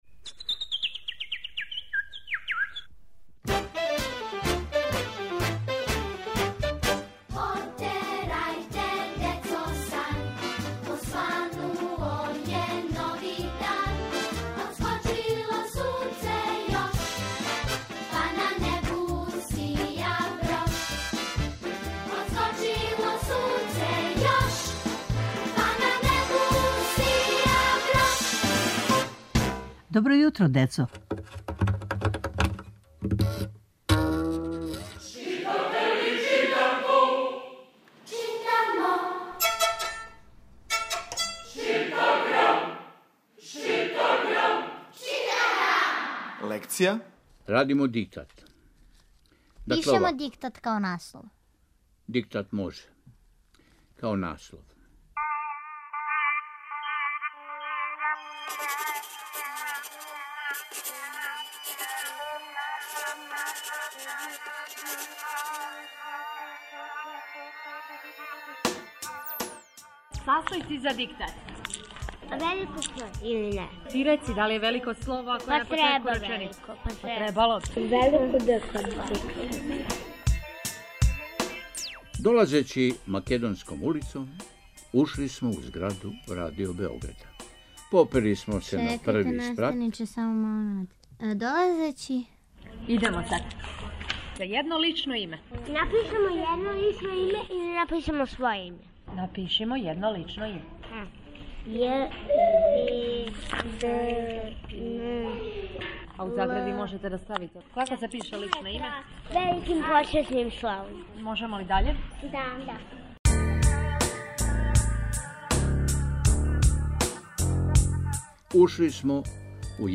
Сваког понедељка у емисији Добро јутро, децо - ЧИТАГРАМ: Читанка за слушање. Ове недеље - први разред, лекција: Диктат.